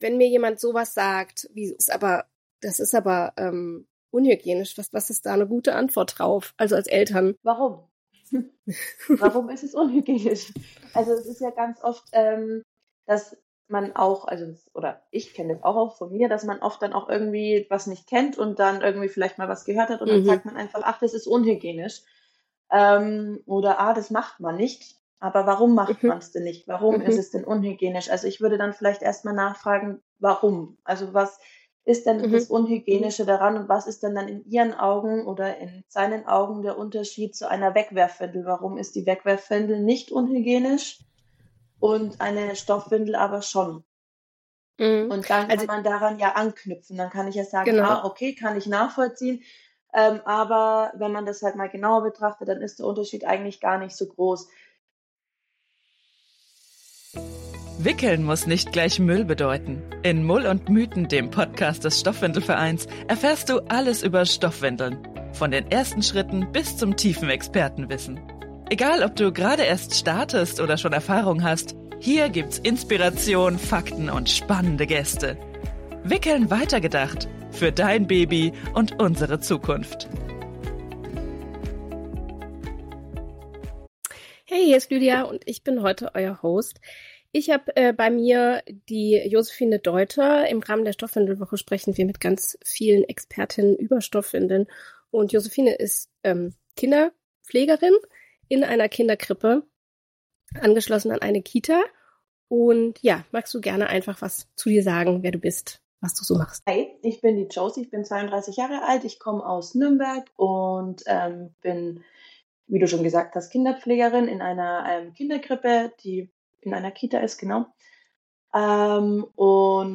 Folge 2 - Wickeln in der Betreuung – Eine Kinderpflegerin berichtet ~ Mull und Mythen Podcast